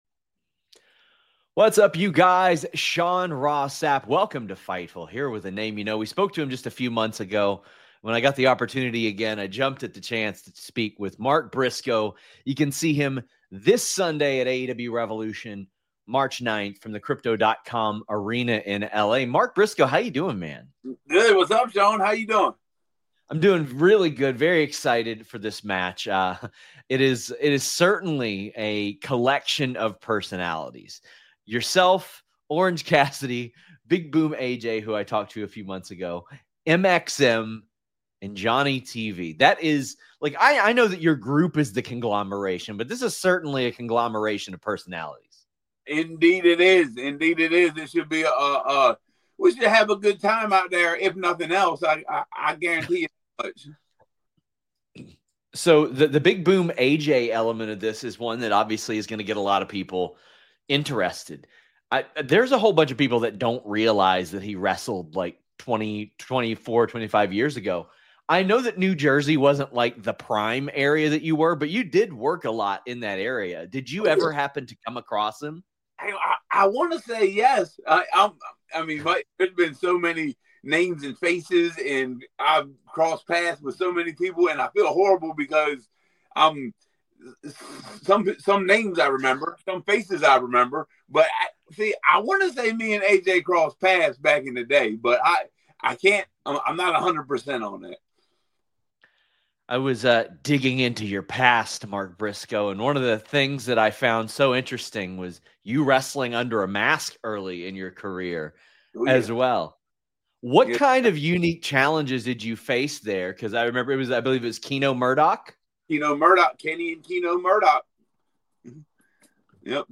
Shoot Interviews